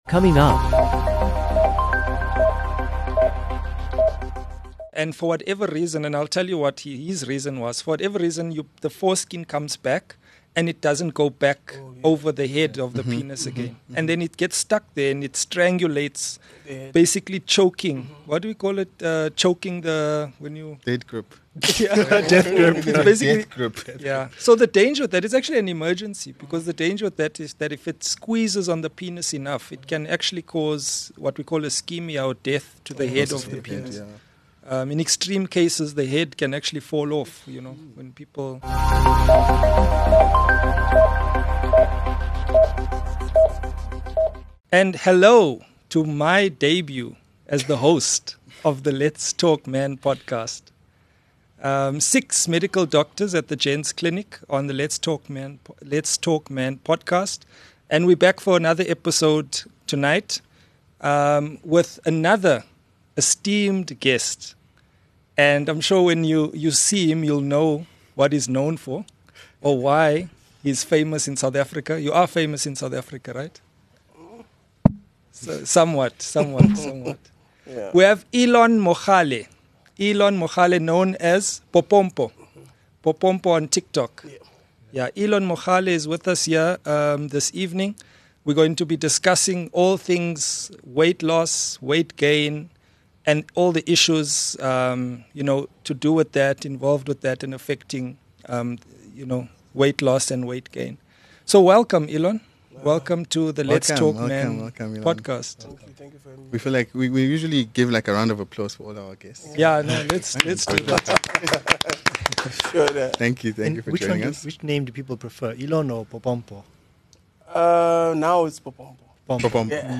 In this deeply honest episode, doctors discuss a condition called paraphimosis, which could lead to the head of the penis falling off.